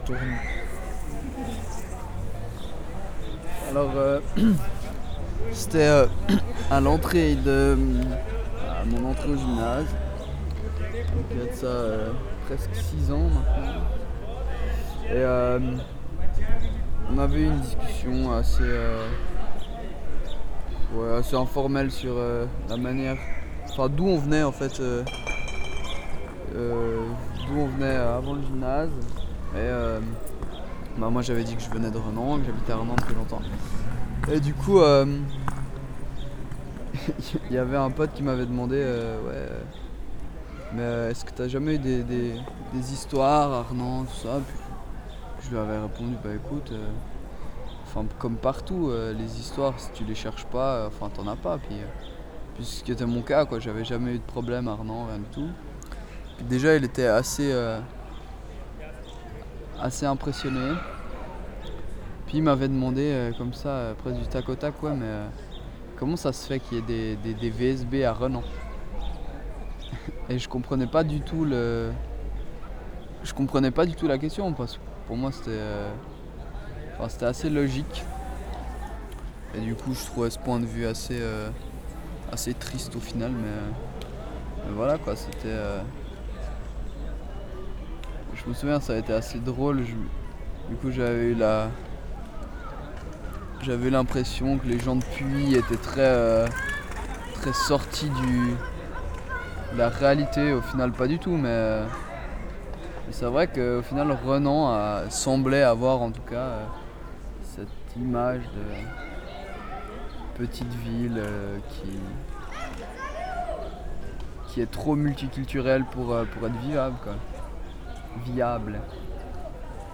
Sur la place du Marché de Renens, je tends mon micro à un ancien gymnasien d’Auguste Piccard.